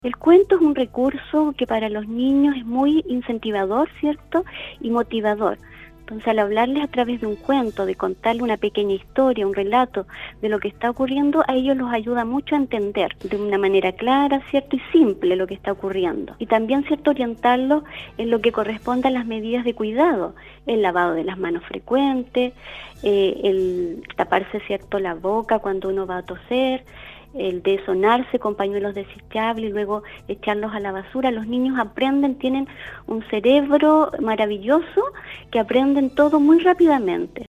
La mañana de este miércoles, y como parte de un aporte a la comunidad atacameña, por el mes de abril se estará realizando una entrevista con el equipo de la Fundación Integra Atacama donde se tocarán temas fundamentales para el desarrollo de los niños y niñas atacameños.